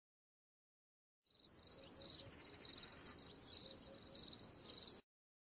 Crickets[1]